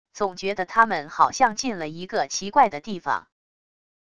总觉得他们好像进了一个奇怪的地方wav音频生成系统WAV Audio Player